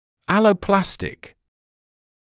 [ˌæləu’plæstɪk][ˌэлоу’плэстик]аллопластический; синтетический остеозамещающий (о материале)